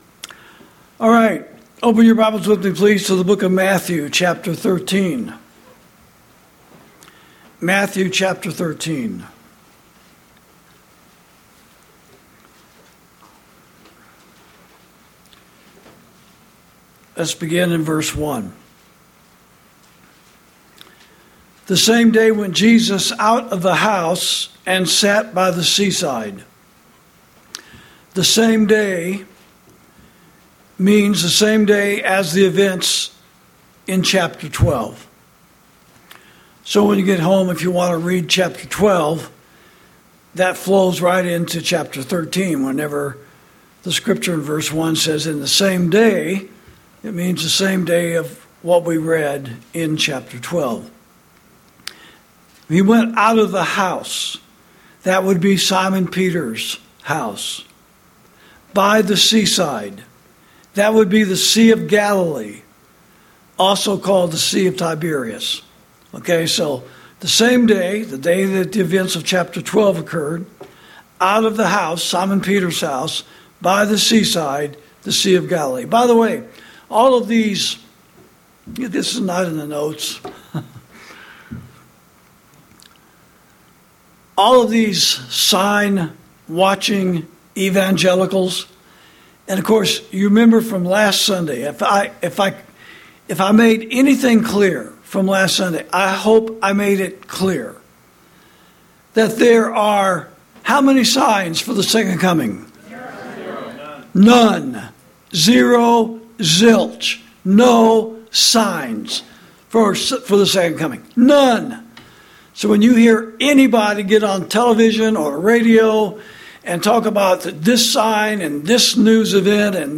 Sermons > Eyes That Do Not See, Ears That Do Not Hear